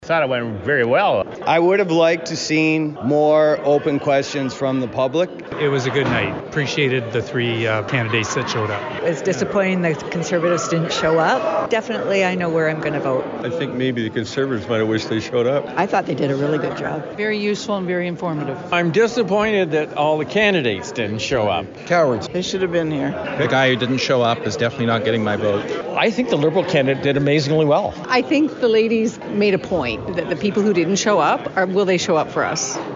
We asked those in attendance what they took away from the evening.